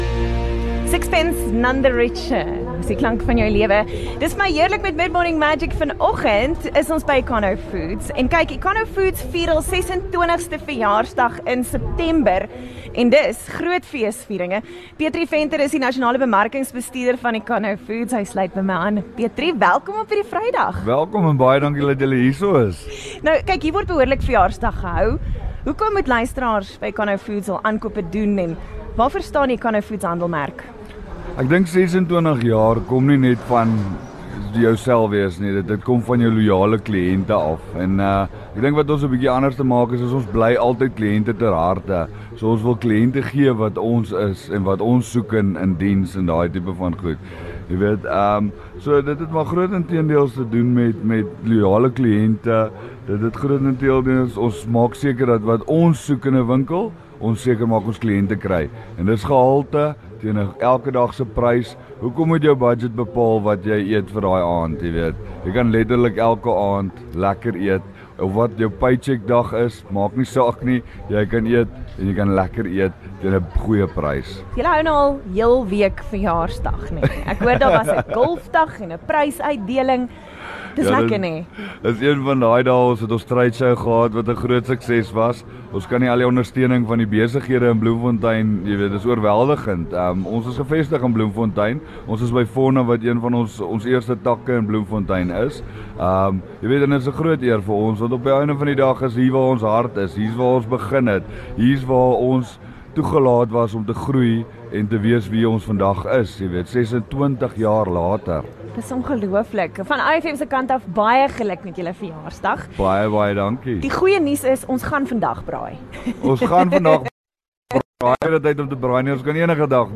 9 Sep Econo Foods 26th Birthday Interview 1
OFM visits Econo Foods in Fauna Bloemfontein, on 9 September.